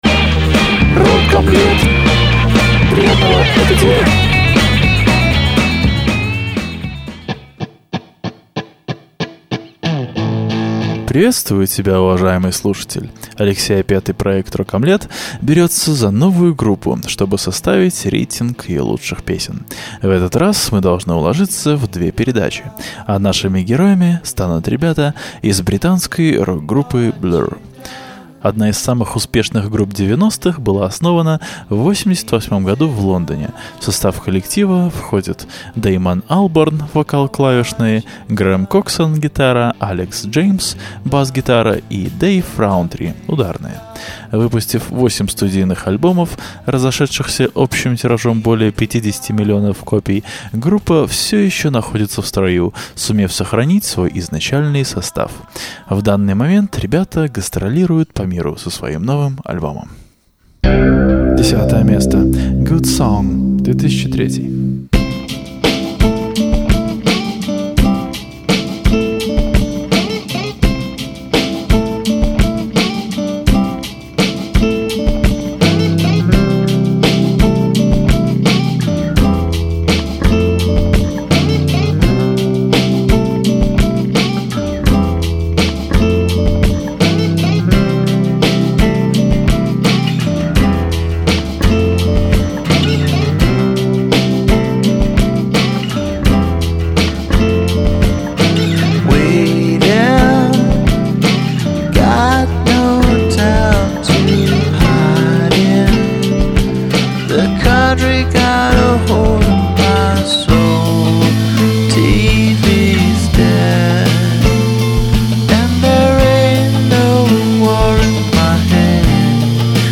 Своеобразная комбинация альтернативной и танцевальной музыки.